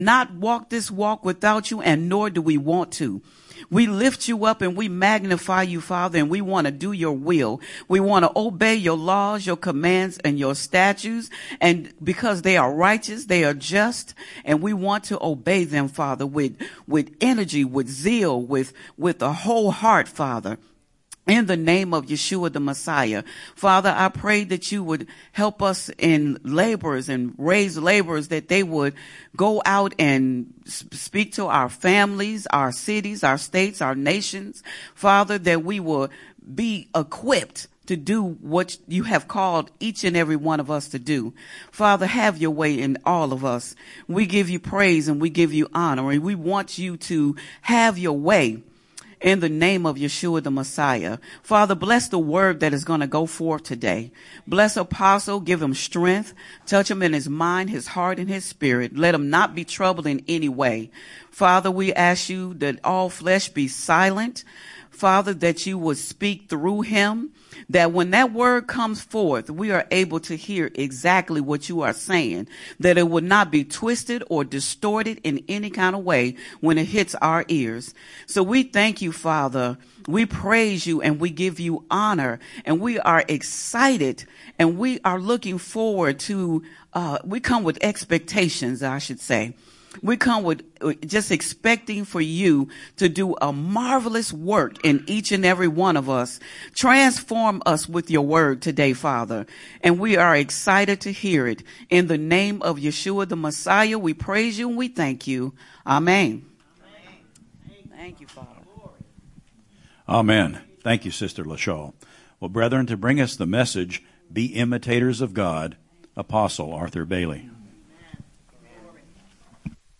Sabbath-Service_-Be-Imitators-of-God_01.mp3